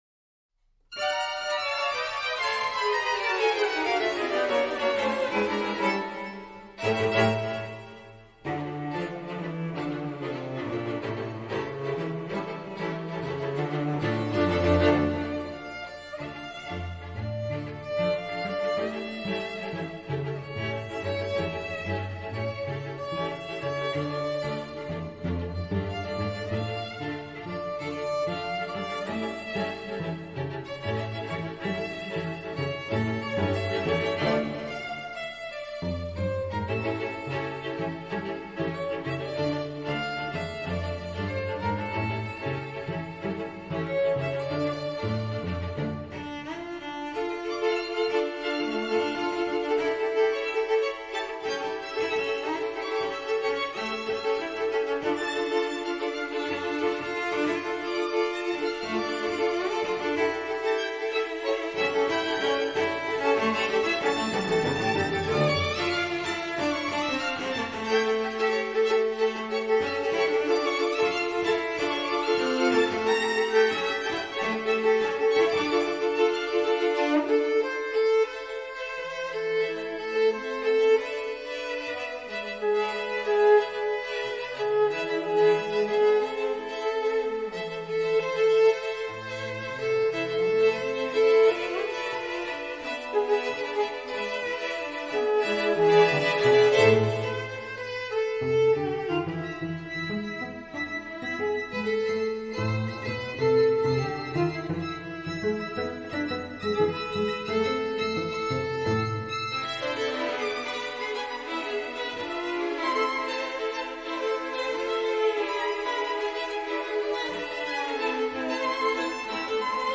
A sizzling, propulsive arrangement for String Quartet